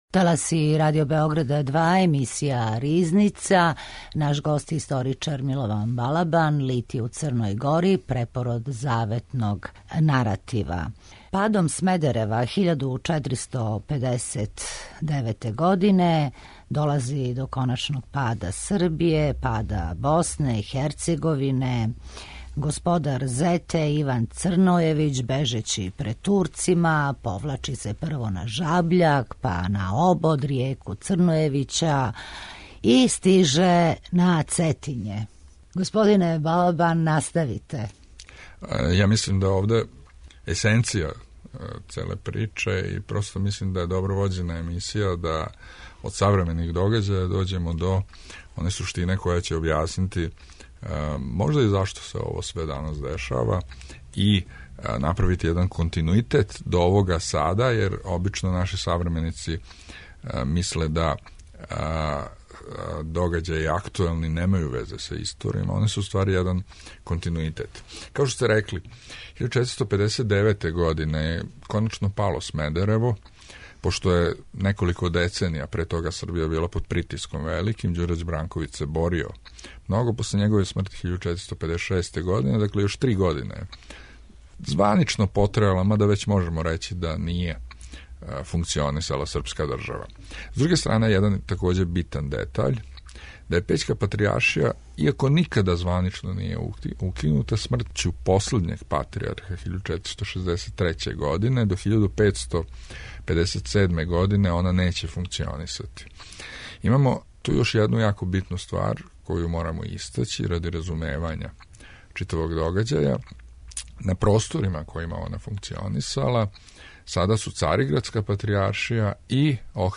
Како кроз призму савременика и историје објаснити вишемесечне, свакодневне литије, број људи који је изненадио све, упорност и истрајност верног народа у Црној Гори, у очувању светиња, духовног идентитета и враћања једној аутентичној културној матрици, оквир је за разговор у овонедељној емисији.